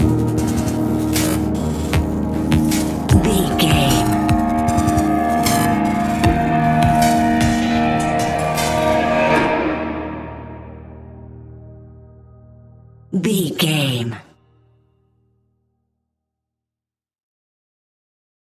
Fast paced
Ionian/Major
D♯
industrial
dark ambient
EBM
drone
synths